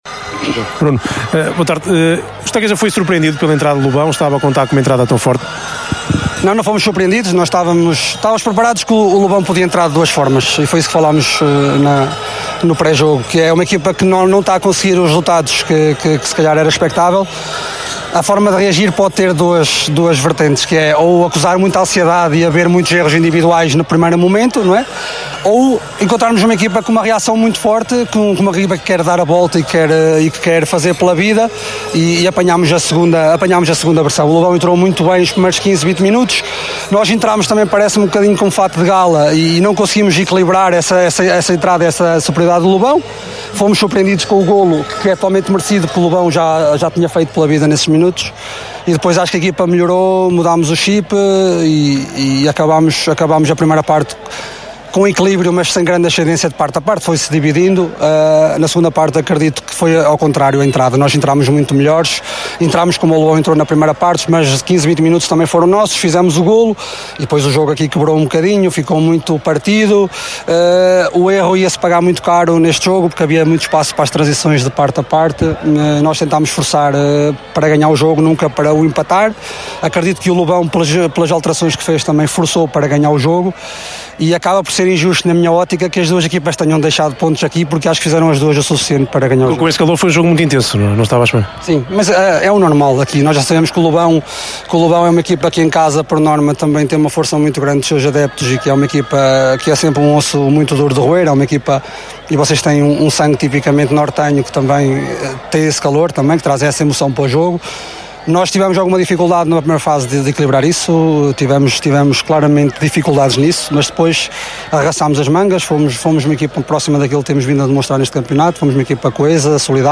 No final do jogo, a Sintonia procurou ouvir os intervenientes de ambas as equipas.